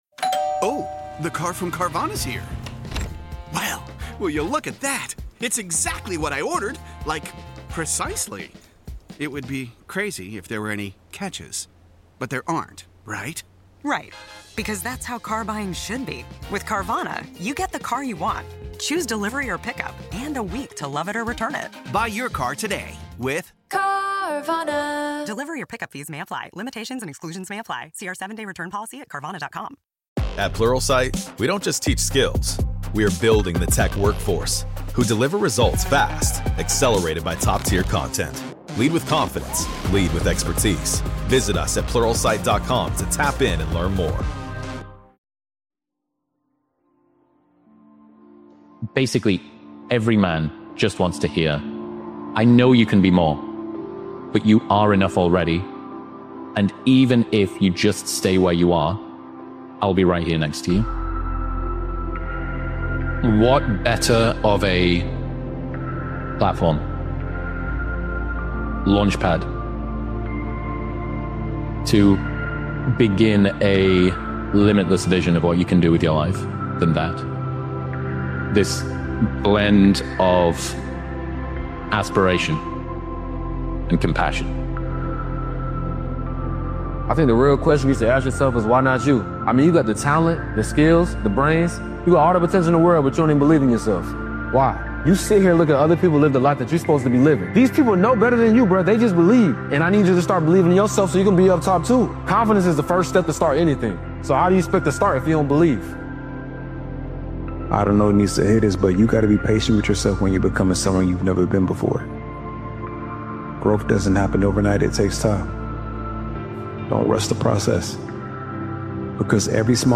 This emotional motivational speeches compilation is a message for anyone standing in the unknown—unsure of the path but certain of the outcome.